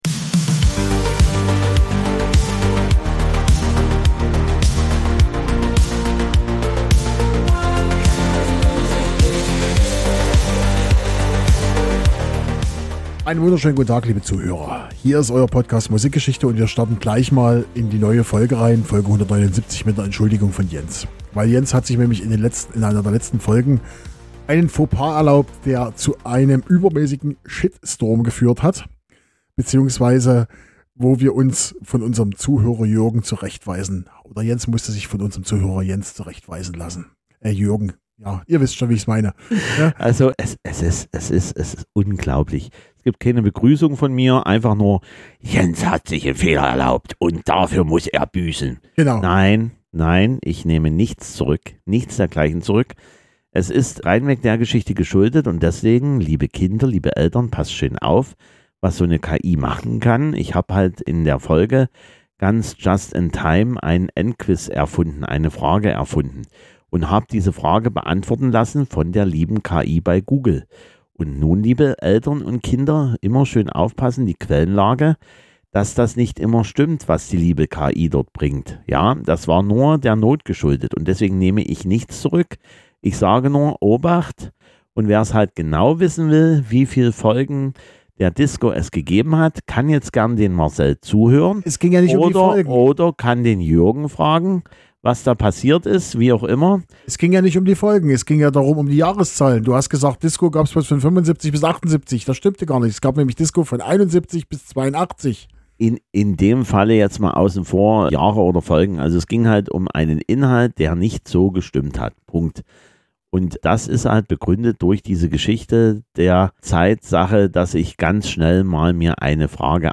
Musikalisch sind wir wieder in den 80ern und das hört man an der Musik ganz genau. Viel Synthesizer, Musik aus Österreich und aus Italien.